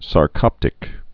(sär-kŏptĭk)